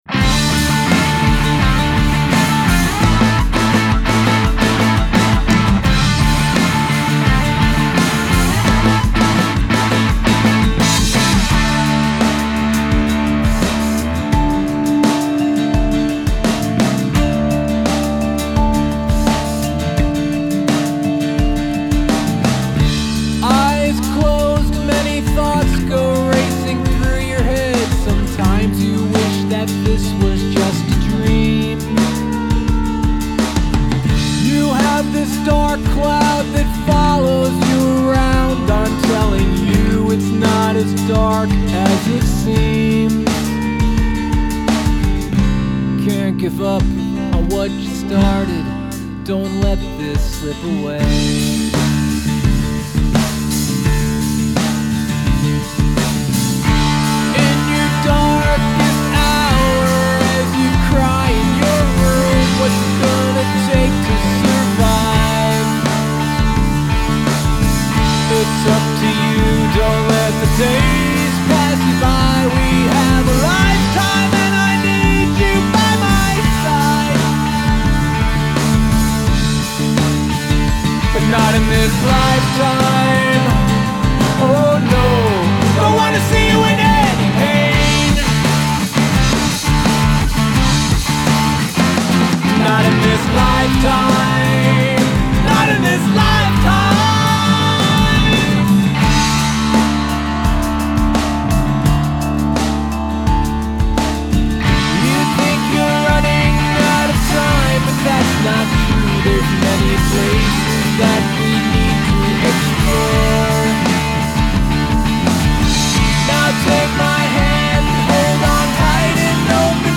Production is great!
Best guitar solo wins you this place on top.